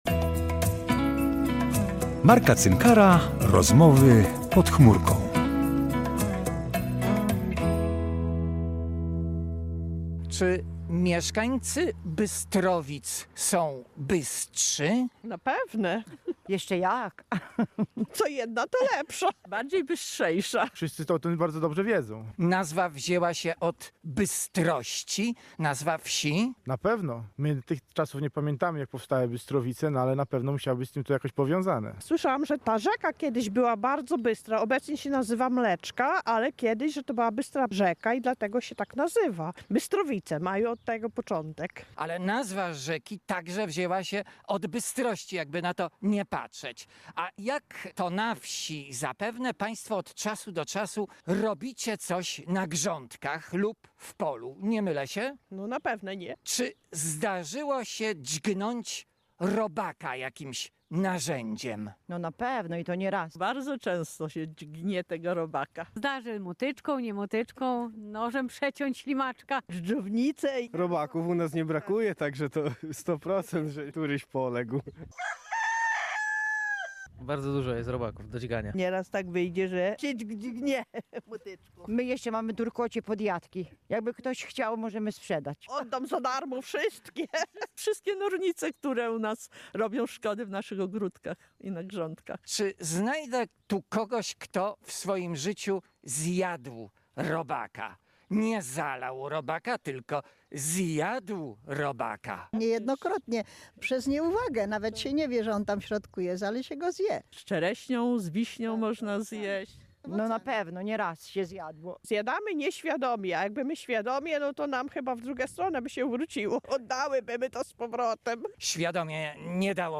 pytał mieszkańców co o tym sądzą?